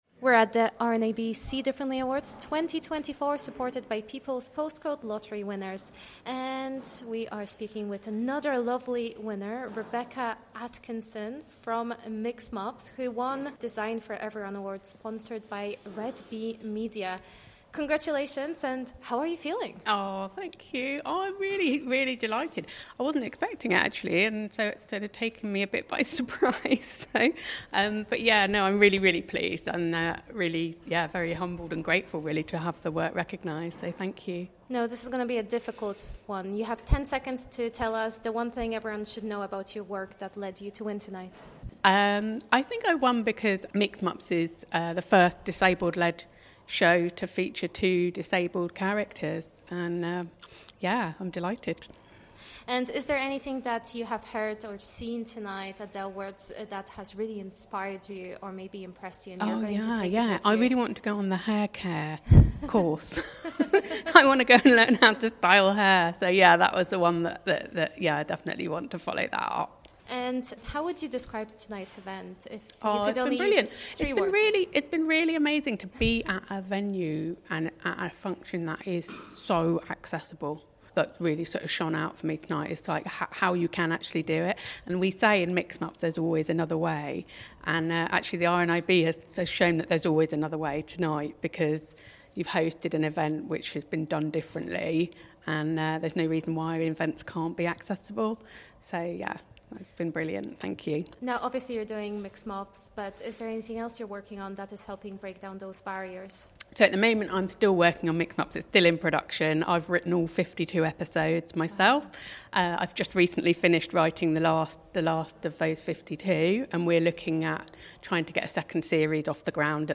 The RNIB See Differently Awards 2024 supported by the People’s Postcode Lottery winners took place in London on Tuesday 21st of May.